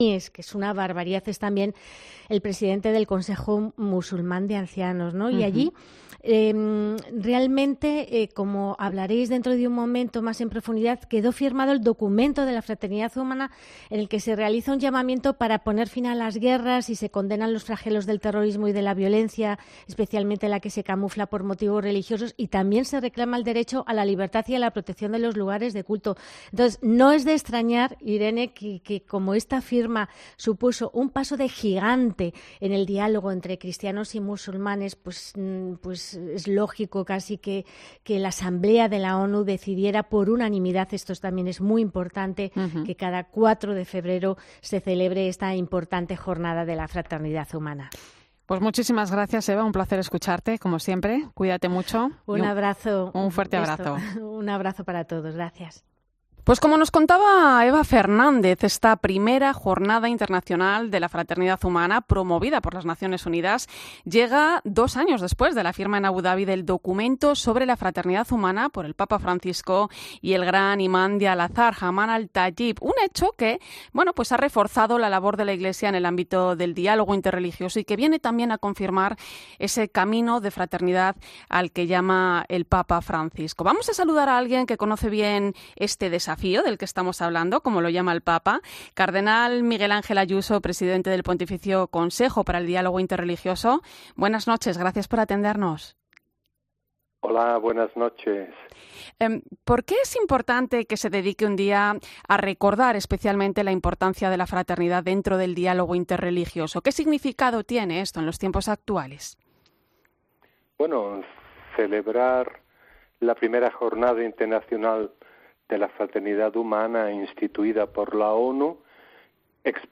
El presidente del Pontificio Consejo del Diálogo Interreligioso, aborda esta jornada en la Linterna de la Iglesia de la Cadena COPE